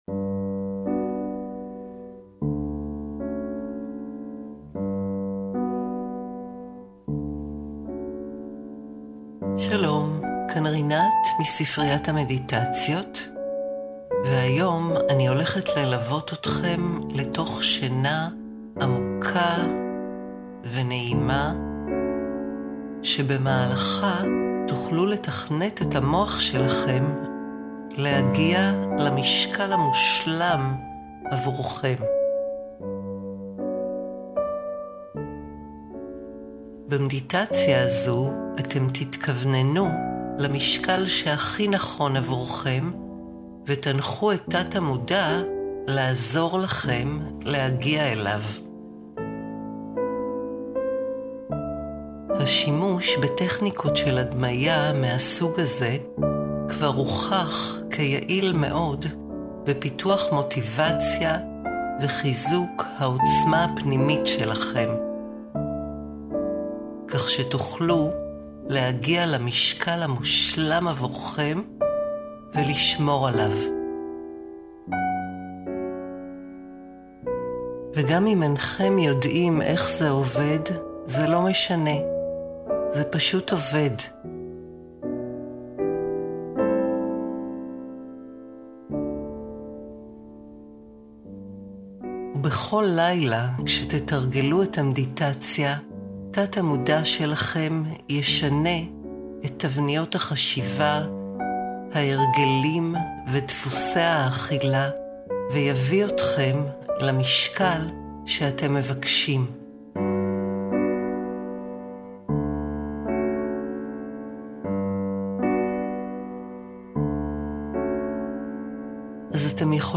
המדיטציה מלווה בהצהרות סאבלימינליות להורדה במשקל ובתדר אימון גלי מוח להרפיה עמוקה (אין צורך באוזניות).
דוגמה מתוך המדיטציה ההיפנותרפית "לרזות תוך כדי שינה":
הורדה במשקל היפנותרפיה ללילה
• הטכניקה שבה הוקלטו: לדוגמה משפטים ארוכים שלא נגמרים, טון דיבור מונוטוני במיוחד ועוד, עוצבו במיוחד כדי להפיל עליכם שינה.